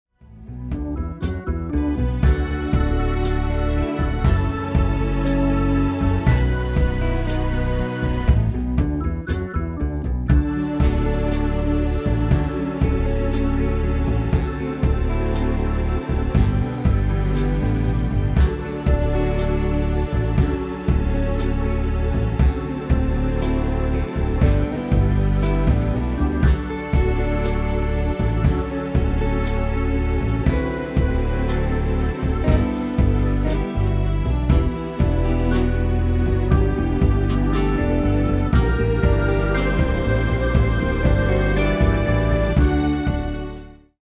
Demo/Koop midifile
Genre: R&B / Soul / Funk
Toonsoort: D
- Vocal harmony tracks
Demo's zijn eigen opnames van onze digitale arrangementen.